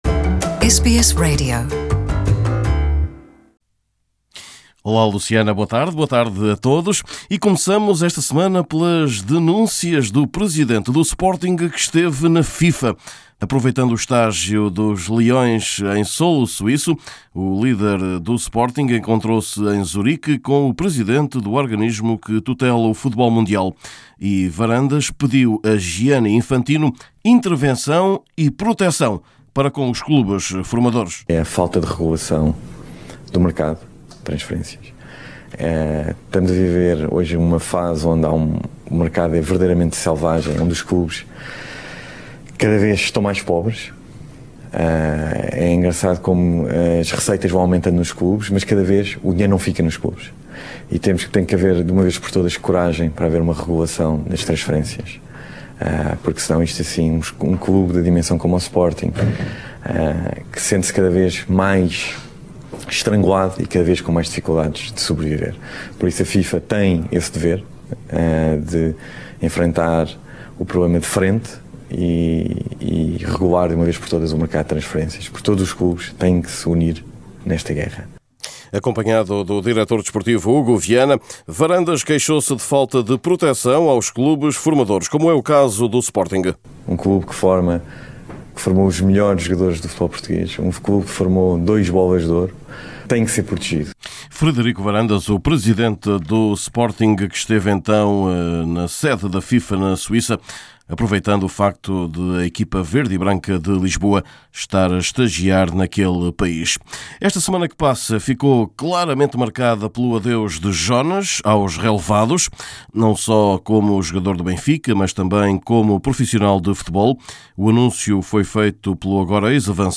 Ainda neste boletim, a despedida dos relvados de um dos jogadores mais emblemáticos da história do Benfica, Jonas, aos 35 anos.